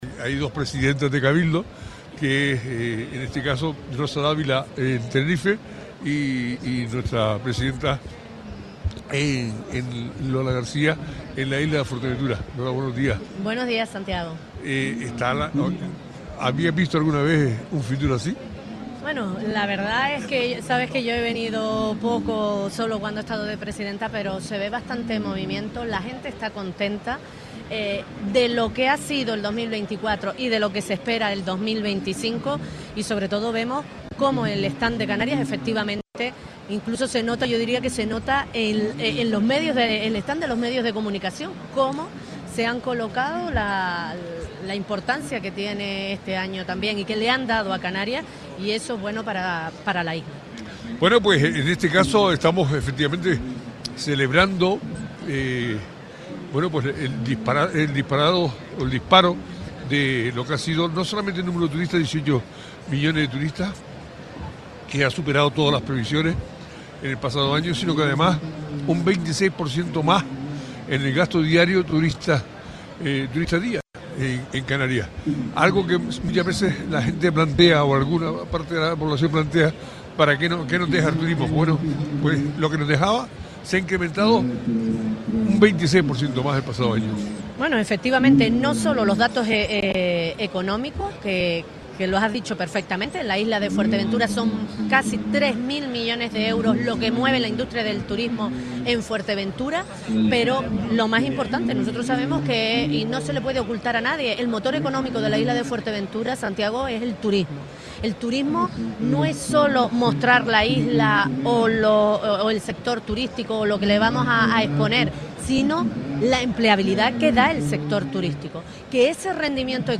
Desde la Feria Internacional de Turismo, hablamos con la presidenta del Cabildo de Fuerteventura, Lola García.